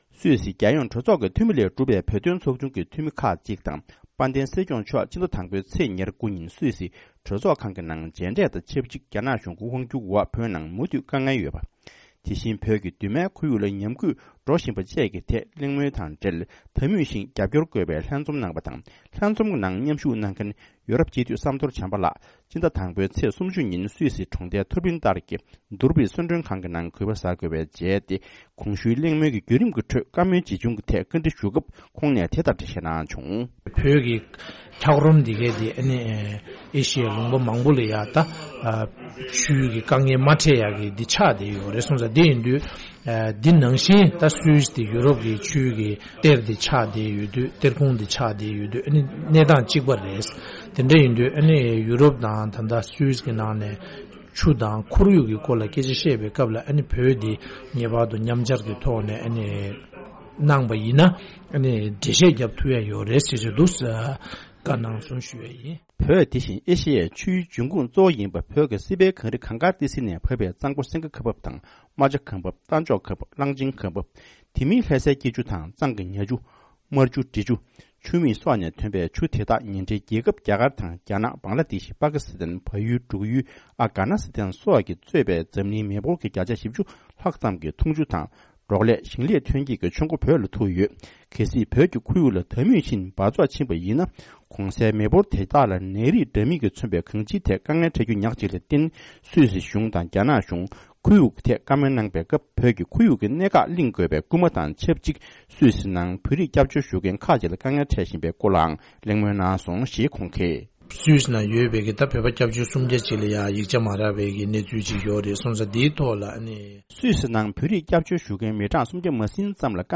ས་གནས་ནས་བཏང་བའི་གནས་ཚུལ་ལ་གསན་རོགས་ཞུ༎